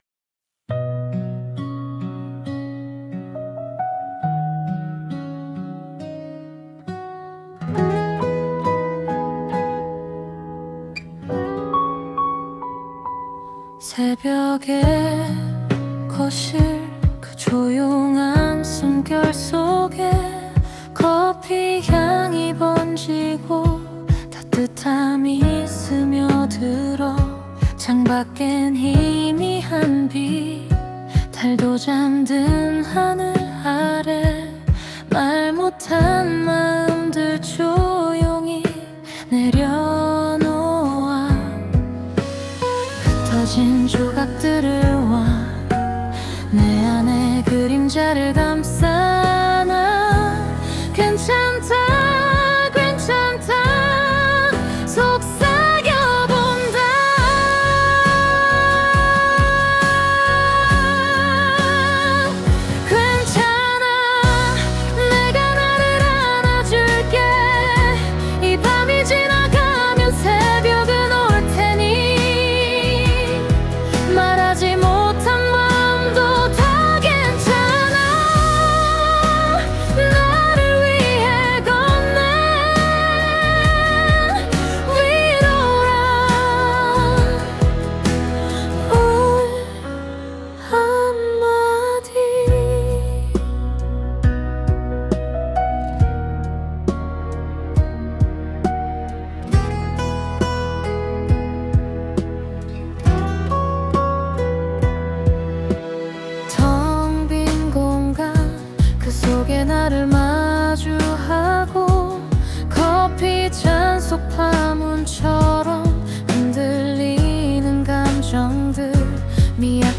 다운로드 설정 정보 Scene (장면) morning_coffee Topic (주제) 새벽 거실, 커피 향, 고요한 숨, 창밖의 미약한 빛. 말하지 못한 마음을 조용히 내려놓고 “괜찮다”를 스스로에게 건네는 위로. Suno 생성 가이드 (참고) Style of Music Acoustic Pop, Warm Piano, Morning Vibe, Female Vocals, Soft Voice Lyrics Structure [Meta] Language: Korean Topic: 새벽 거실, 커피 향, 고요한 숨, 창밖의 미약한 빛. 말하지 못한 마음을 조용히 내려놓고 “괜찮다”를 스스로에게 건네는 위로.